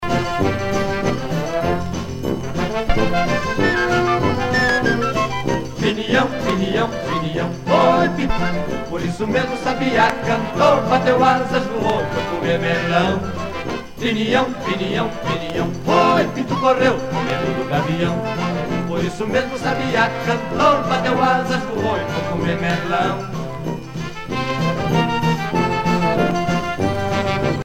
danse : samba